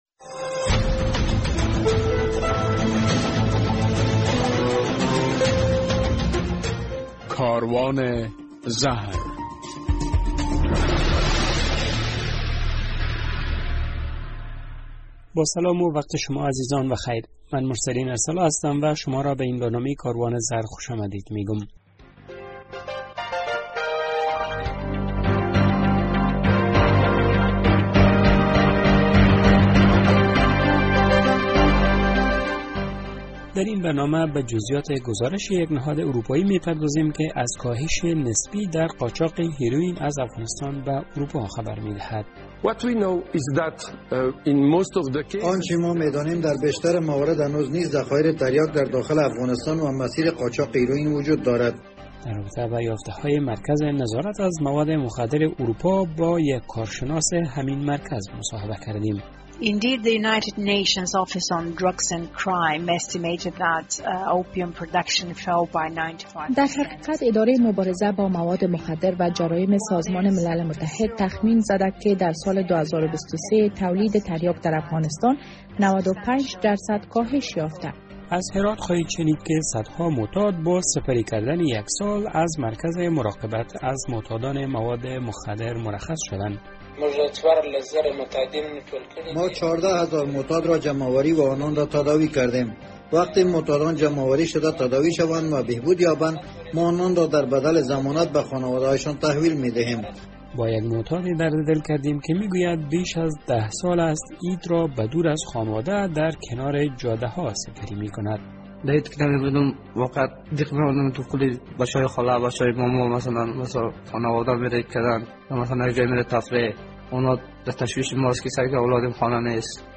در برنامه این هفته «کاروان زهر» جزئیات یک گزارش تازه در رابطه به کاهش قاچاق هیرویین از افغانستان به اروپا و برعکس افزایش قاچاق شیشه را می‌شنوید. در مصاحبه با یک کارشناس مرکز نظارت از مواد مخدر اتحادیه اروپا در رابطه به جزیات همین گزارش مصاحبه کرده ایم.